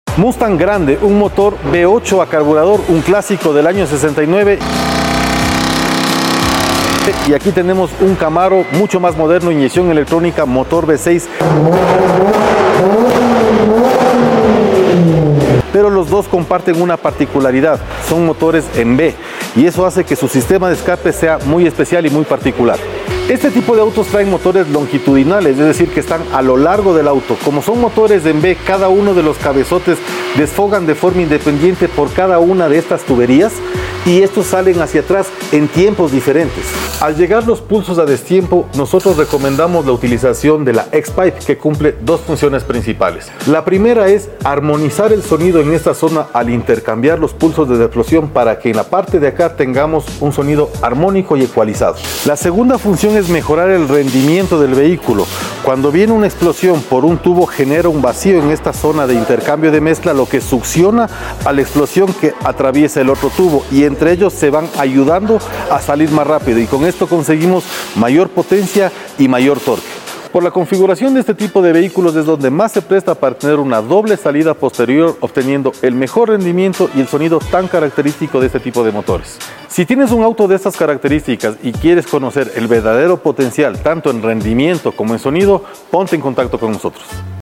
Los motores en V son sound effects free download
Los motores en V son sinónimo de potencia y rendimiento. En especial, el Mustang Grande 69 y el Chevrolet Camaro V6 destacan por su agresivo sonido y su capacidad para ofrecer una experiencia de conducción única.